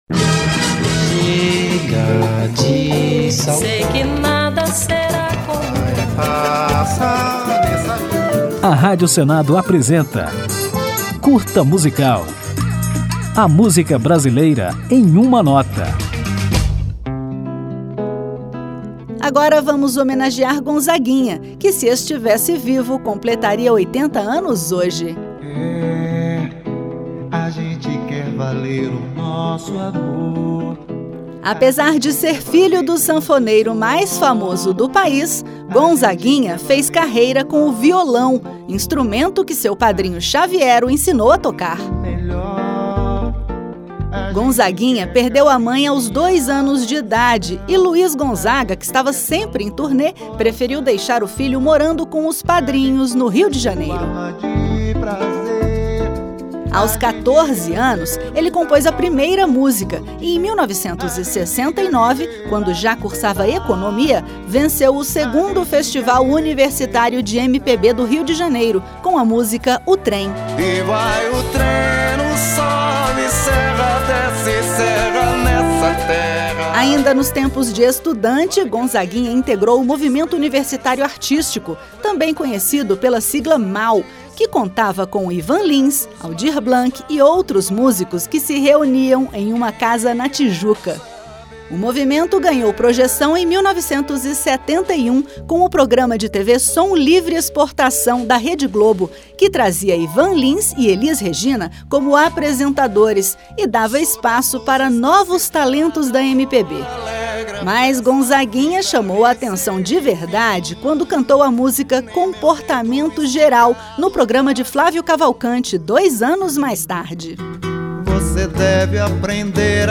Nesta homenagem, contaremos a história do artista, que se encerrou num acidente e carro fatal. E ainda ouviremos Gonzaguinha em seu maior sucesso: O Que É o Que É.